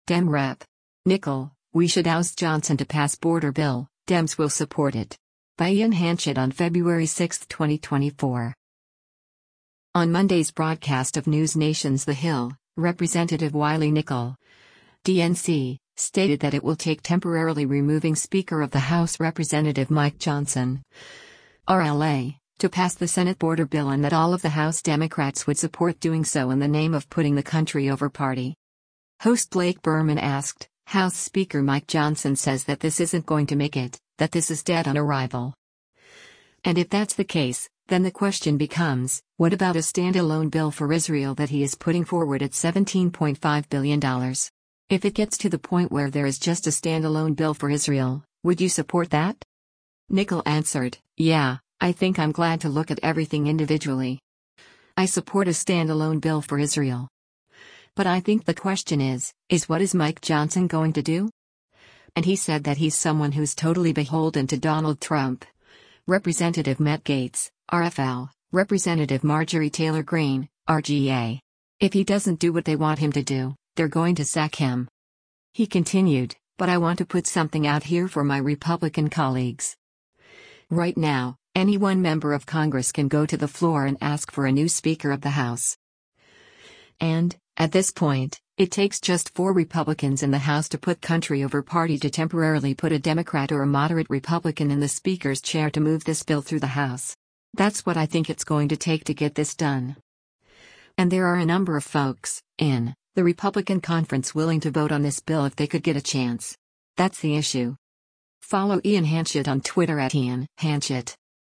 On Monday’s broadcast of NewsNation’s “The Hill,” Rep. Wiley Nickel (D-NC) stated that it will take “temporarily” removing Speaker of the House Rep. Mike Johnson (R-LA) to pass the Senate border bill and that all of the House Democrats would support doing so in the name of putting the country over party.